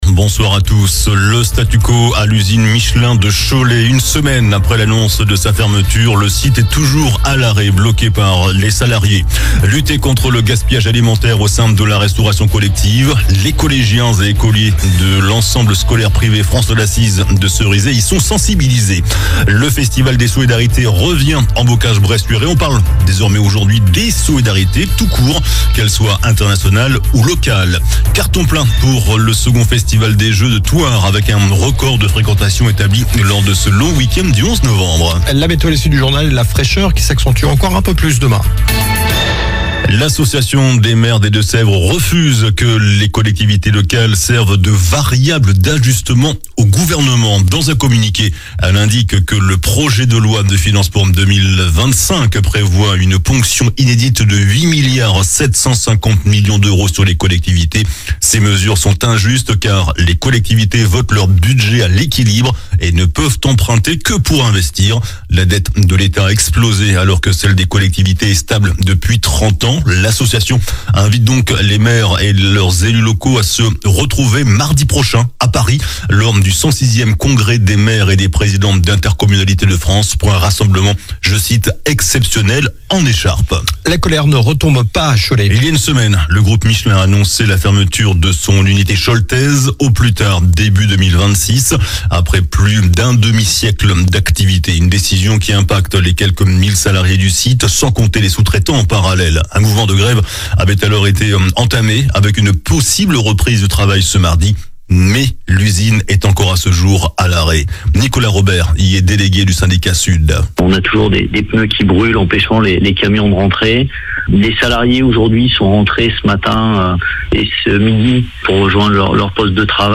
JOURNAL DU MARDI 12 NOVEMBRE ( SOIR )